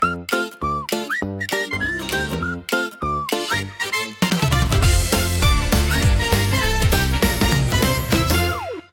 funny